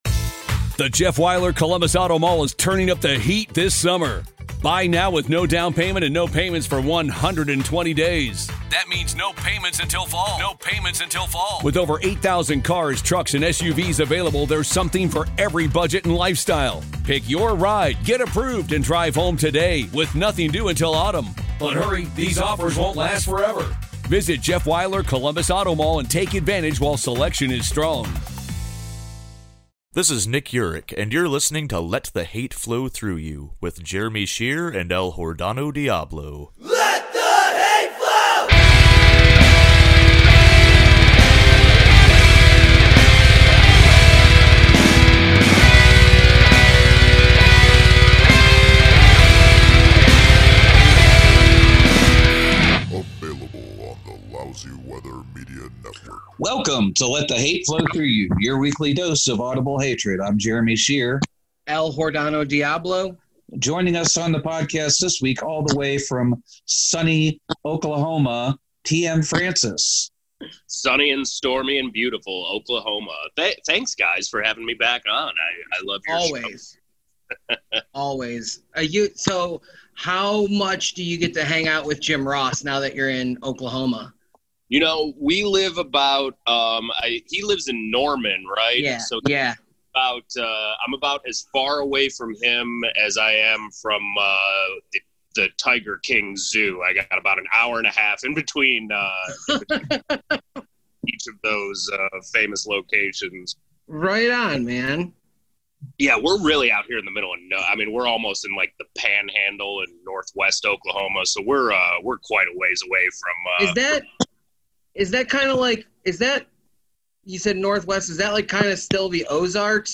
Find out this week, plus quarantine updates from CLE and OK. Recorded with Zoom.